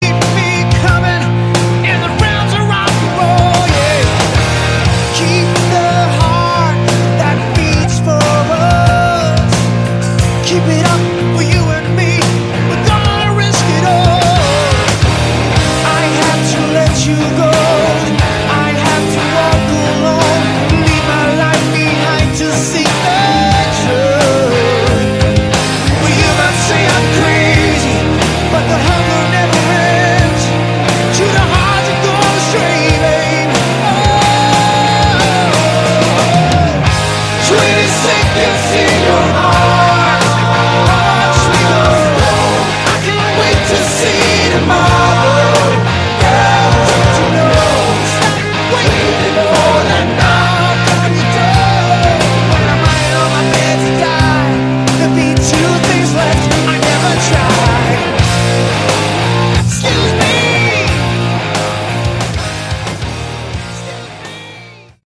Category: Melodic Rock
guitar
lead vocals
bass, backing vocals
drums